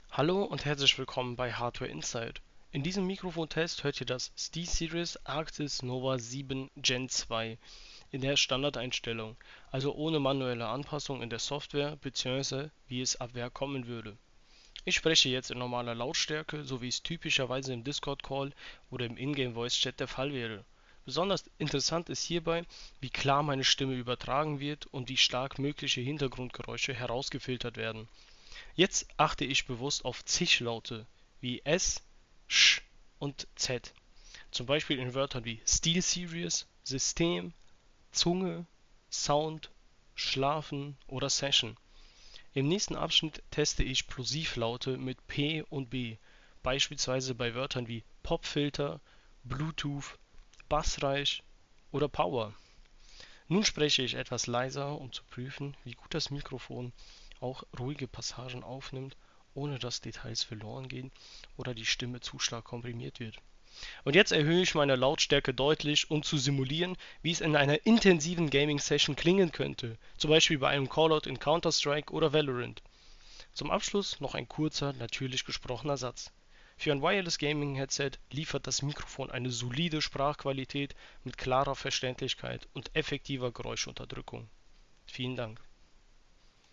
Für unsere Testaufnahme wurden keinerlei Einstellungen in der SteelSeries GG-Software verändert. Standardmäßig ist ClearCast AI Noise Cancellation aktiviert. Diese KI-gestützte Geräuschunterdrückung filtert Hintergrundgeräusche wie Tastaturanschläge, Mausklicks oder leise Umgebungsgeräusche sehr effektiv heraus.
Die Stimme selbst hört sich weiterhin natürlich und nicht komprimiert an.
Technisch arbeitet das Mikrofon im Standardformat mit 2 Kanälen bei 24-Bit und 48.000 Hz, wie in den Soundoptionen von Windows 11 ersichtlich ist.
Insgesamt liefert das Mikrofon eine solide bis gute Sprachqualität mit klarer Verständlichkeit und starker Geräuschfilterung.
Steelseries-Arctis-Nova-7-Gen-2-Mikrofontest.mp3